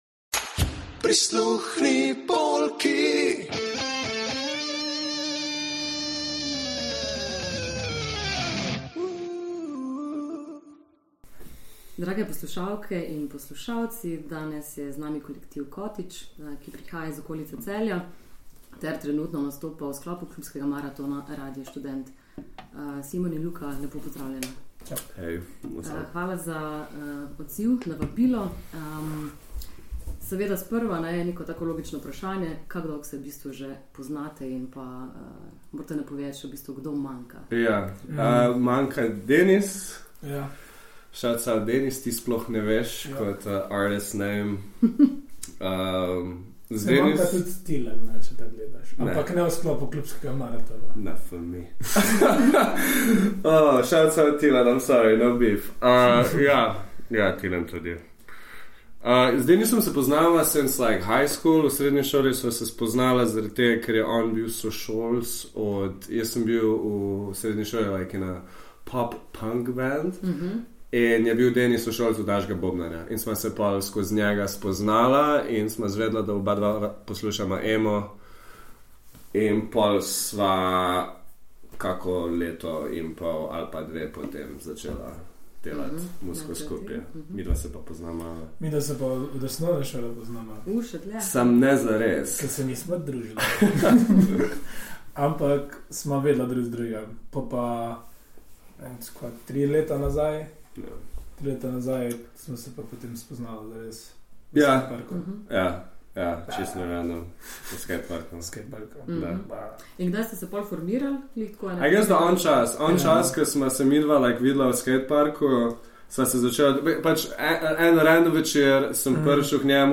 Za tokratno edicijo se pogovarjamo in razpredamo s kolektivom cottage iz Celja in okolice, ki ga sestavljajo trije pobje. Skupino zaznamuje autotune rimoklepaško izlivanje tegob, ki ga podlaga traperski inštrumental.